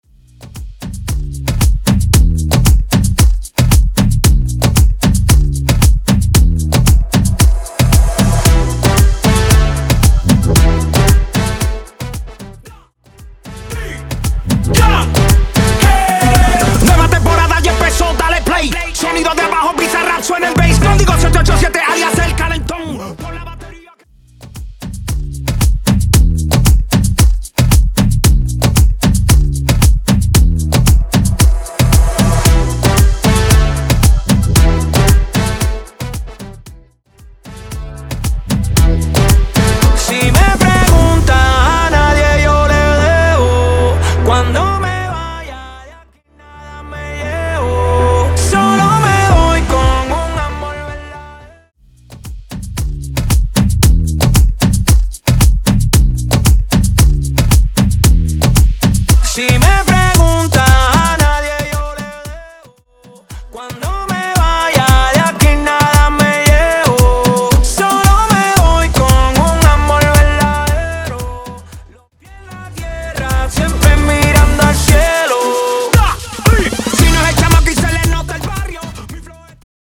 Intro Dirty, Pre Coro Dirty, Pre Coro Acapella Dirty